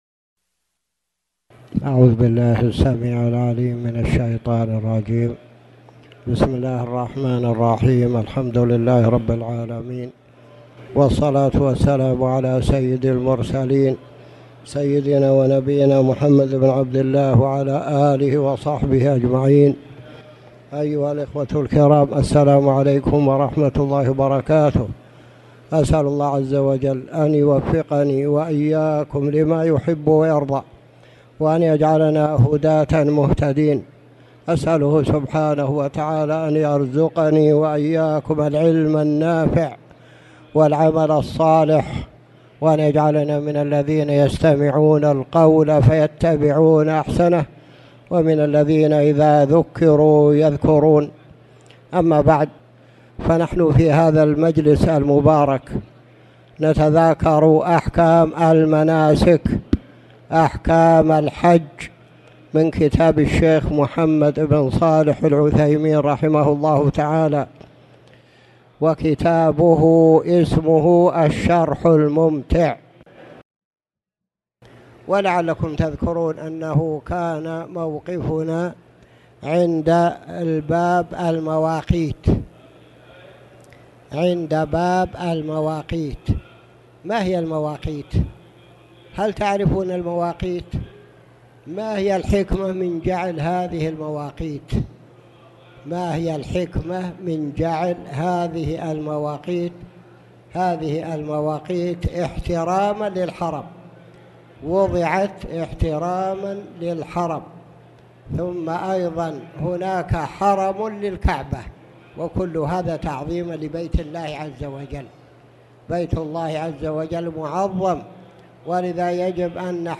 تاريخ النشر ٦ ذو القعدة ١٤٣٨ هـ المكان: المسجد الحرام الشيخ